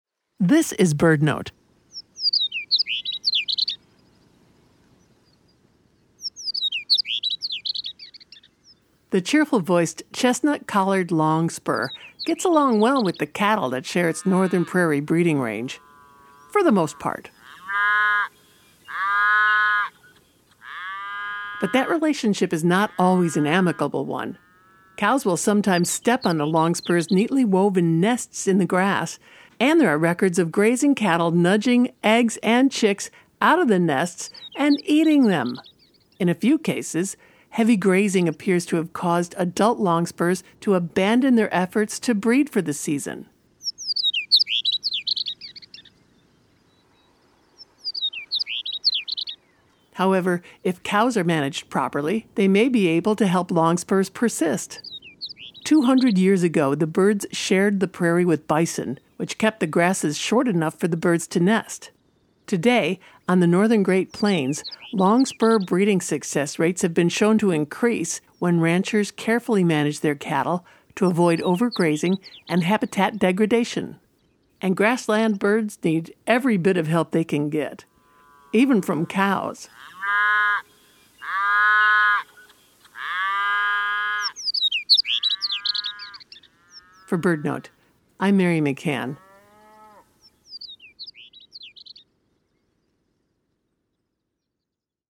The cheerful-voiced Chestnut-collared Longspur shares their northern prairie breeding range with grazing cattle. Although heavy grazing can have adverse effects, breeding densities of longspurs jump by two, three, or even 10 times when ranchers graze their cattle responsibly on native prairies.